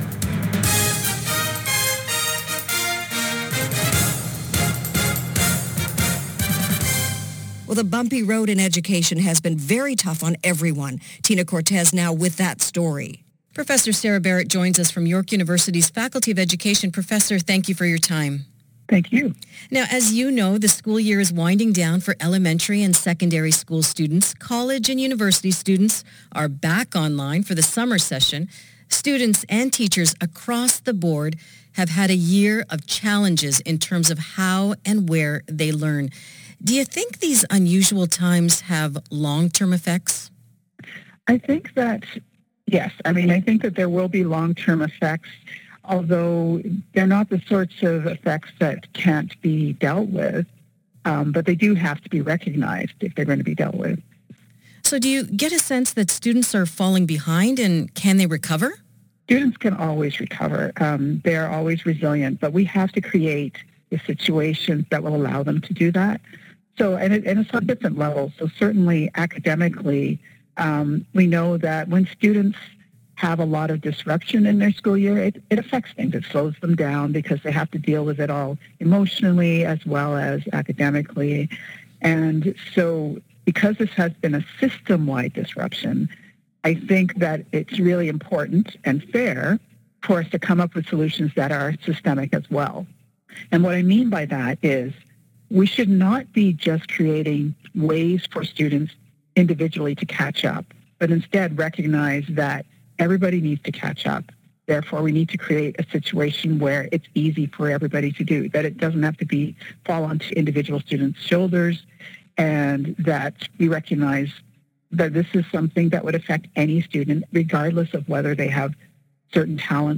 (online) radio segment (this recording is no longer available anywhere else online)